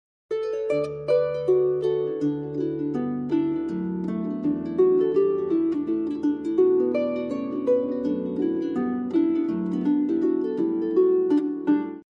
Play, download and share 1_BeepPart_1 original sound button!!!!
1-beeppart-1.mp3